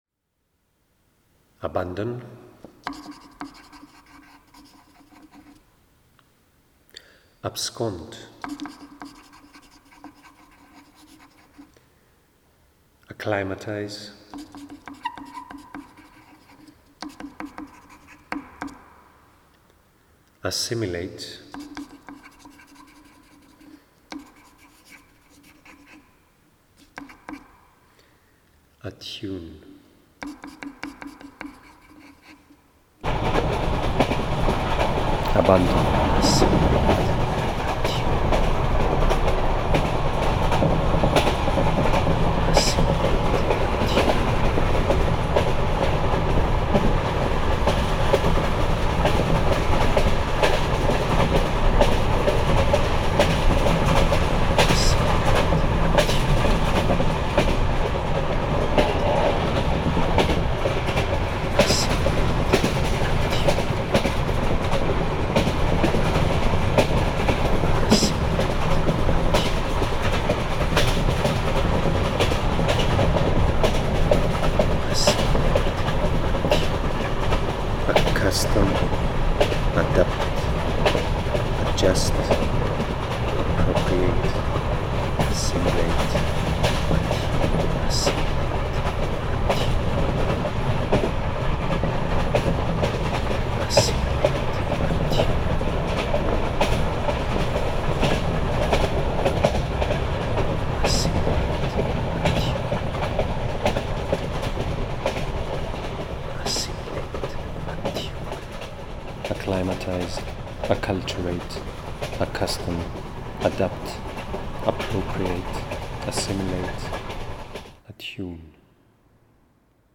Audio Work & Lexicographical Collage
Sound & Collage
The work consists of a series of audio recordings of micro-performances, during which I proclaim all the verbs I could find in the Oxford Dictionary that relate to departure and to arrival. To produce the work I literally took the dictionary on a journey through Europe, visiting border passages, ports, bus and train stations, motorways, airstrips, waiting lounges and suburban parking lots. Whenever I found an intriguing sonic ambience I started to perform, using the unanticipated sonic qualities of those places as resonators of new meaning.